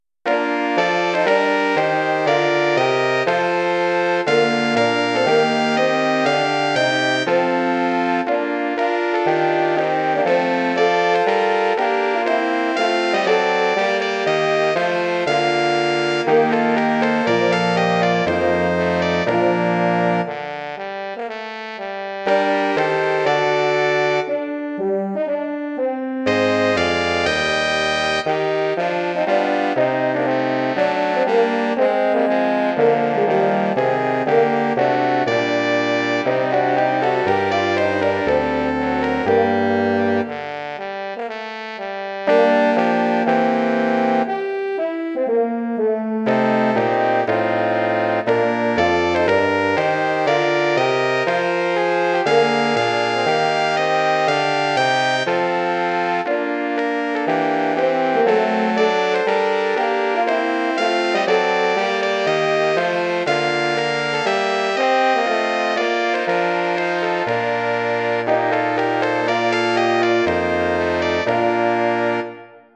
Bearbeitung für Blechbläserquintett
Besetzung: 2 Trompeten, Horn, Posaune, Tuba
arrangement for brass quintet
Instrumentation: 2 trumpets, horn, trombone, tuba